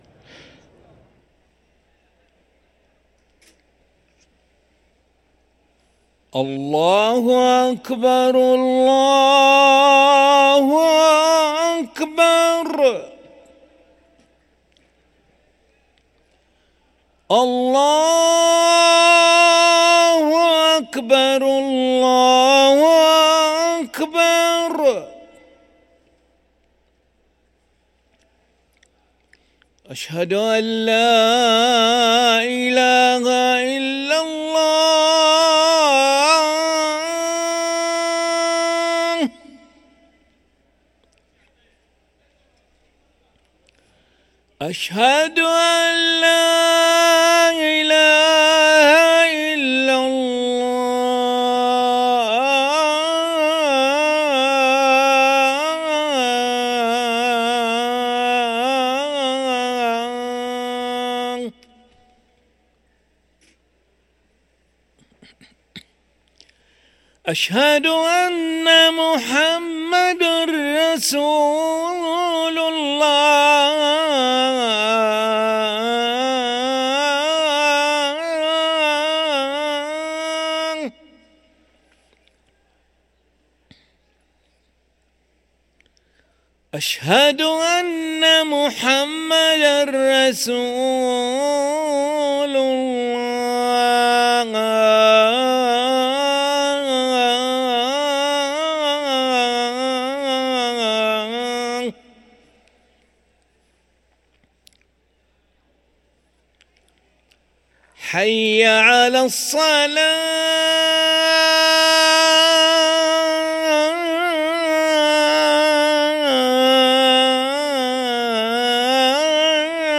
أذان العشاء للمؤذن علي أحمد ملا الأحد 2 ربيع الأول 1445هـ > ١٤٤٥ 🕋 > ركن الأذان 🕋 > المزيد - تلاوات الحرمين